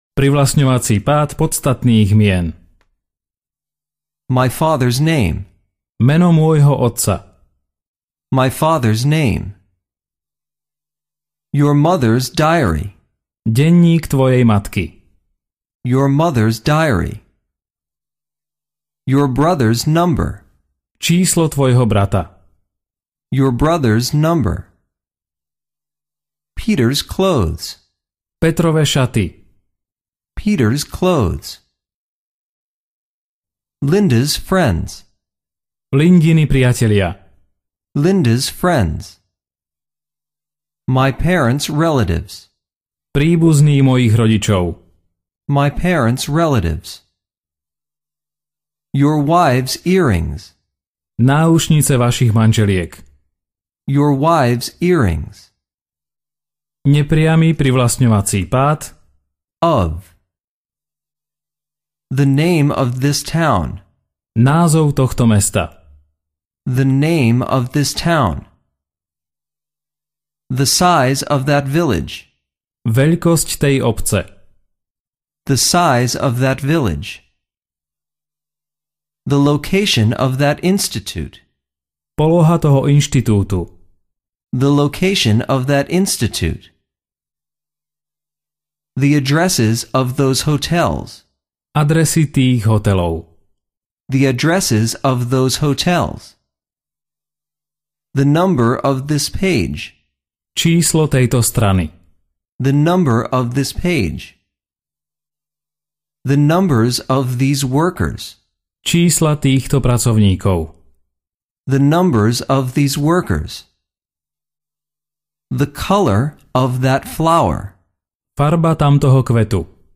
Ukázka z knihy
Každú vetu počujete najprv po anglicky, potom v slovenskom preklade a znovu v originálnom znení.
Príkladové vety nahovoril rodený Angličan.